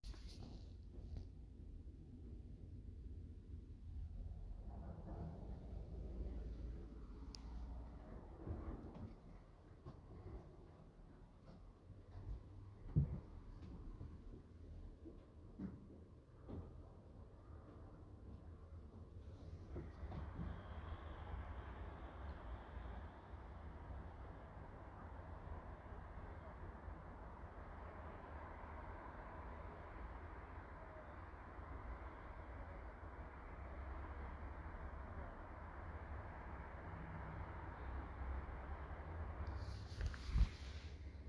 During one of the tasks, we were asked to listen to the room and to objects. This is one of the (short) recordings I made while listening through a crack in the door.
en mobilerecording